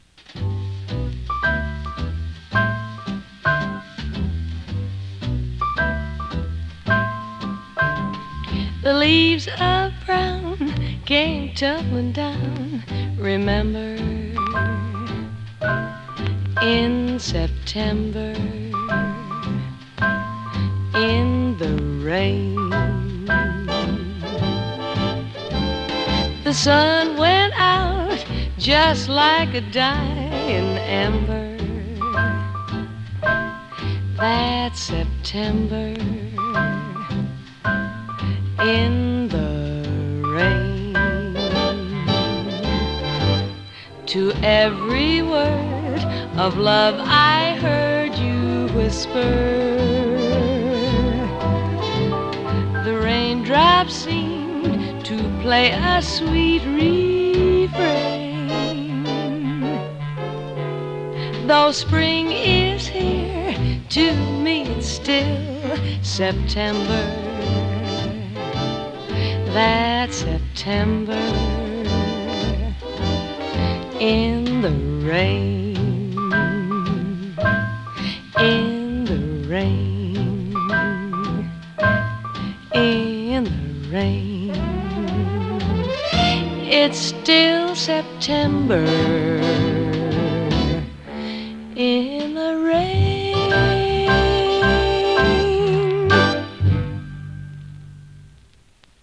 カリフォルニアはサンタローザ出身の白人シンガー。
所々軽いパチ・ノイズ。